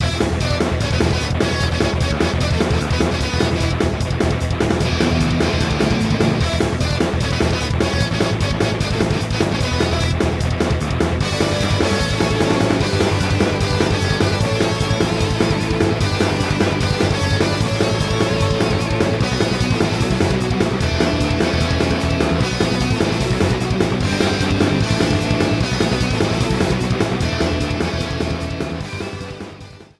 Trimmed and fade out
Fair use music sample